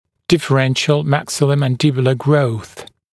[ˌdɪfə’renʃl mækˌsɪlə(u)ˌmæn’dɪbjulə grəuθ][ˌдифэ’рэншл мэкˌсило(у)ˌмэн’дибйулэ гроус]дифференцированный рост верхней и нижней челюстей